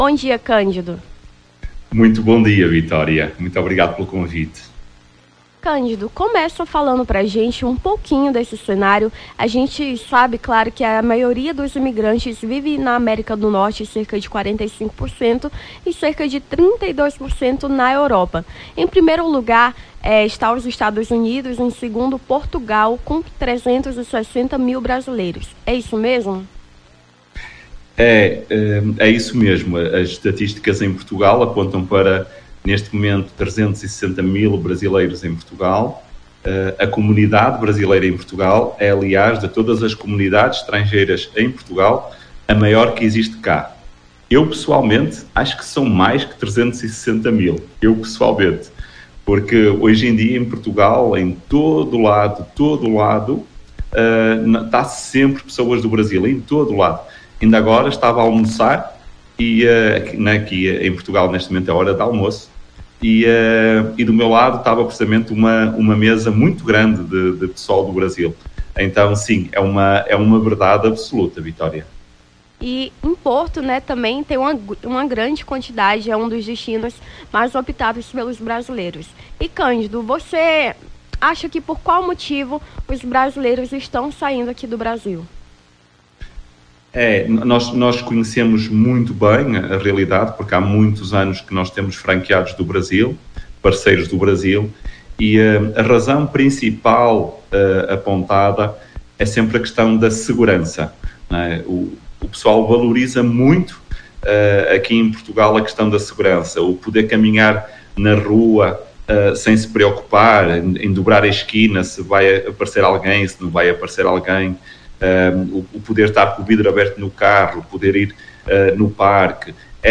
Nome do Artista - CENSURA - ENTREVISTA (BRASILEIROS NO EXTERIOR) 14-09-23.mp3